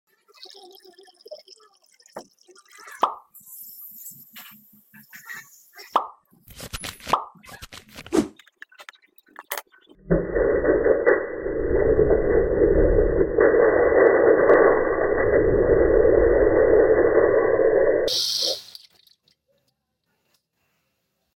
Red & Blue Water Mixing sound effects free download
Red & Blue Water Mixing in a Blender Satisfying ASMR